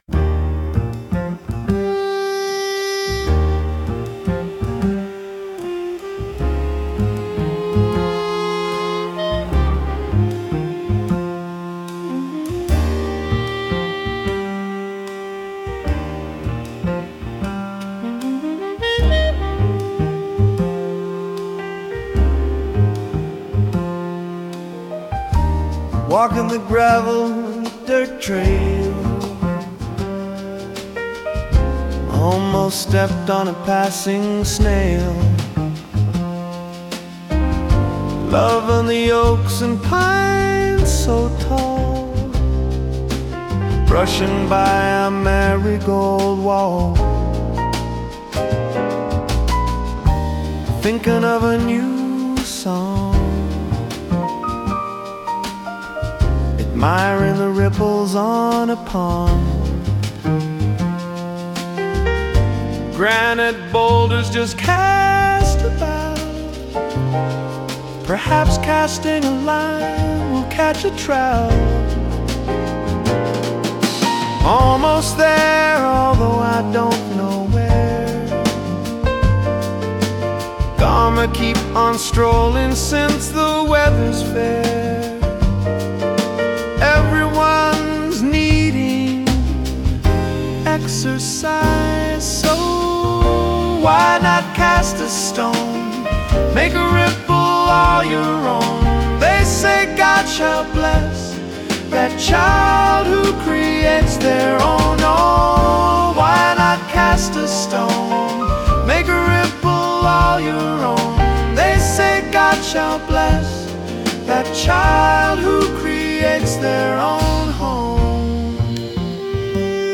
Smooth jazz you can play on repeat